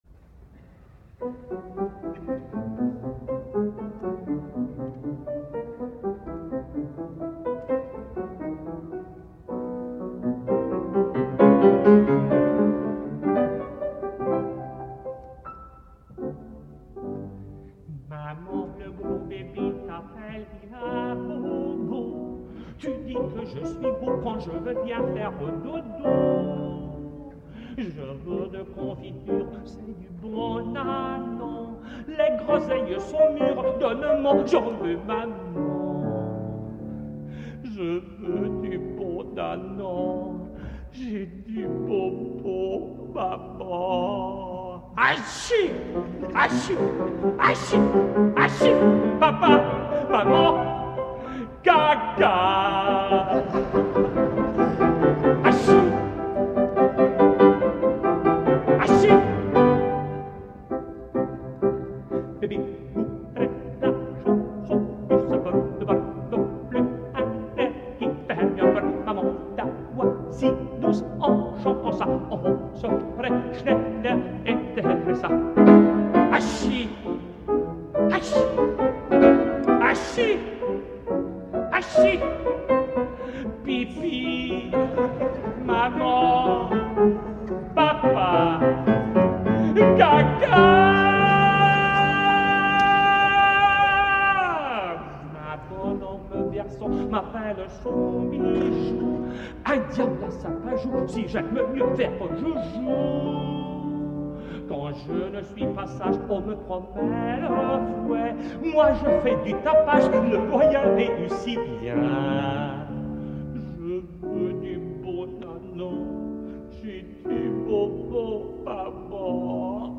És evident que a part de la poca-soltada rossiniana, un pecat més de vellesa (en té de gloriosos), pertanyent al llibre número 11, la cançó és un vehicle perfecta per tal de que el cantant que l’interpreta tregui tota la seva vis còmica i pugui deixar anar tota l’adrenalina acumulada en un recital, imitant la veu punyent i impertinent dels nadons quan es posen “simpàtics”.
El públic s’ho passa tan bé com el tenor.
Nicolai Gedda, tenor
piano
Concertgebouw, Amsterdam 28 de març de 1981